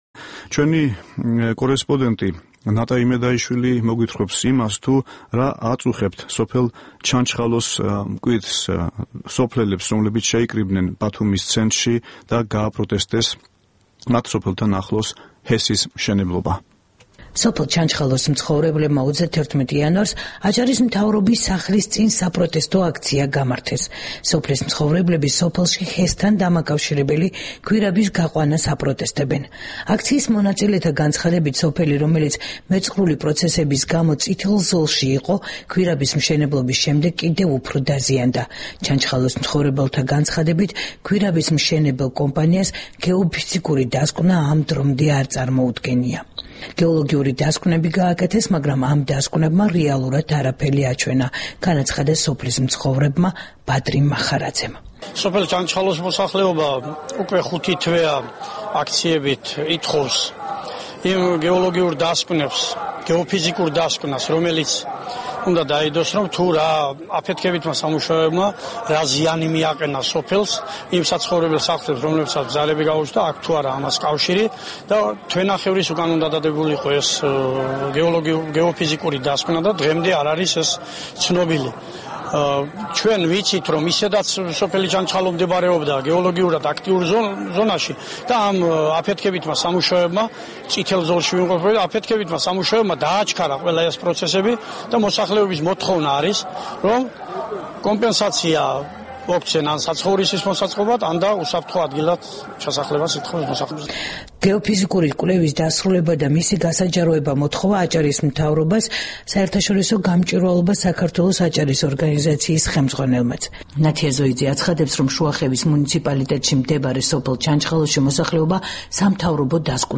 რეპორტაჟი ბათუმიდან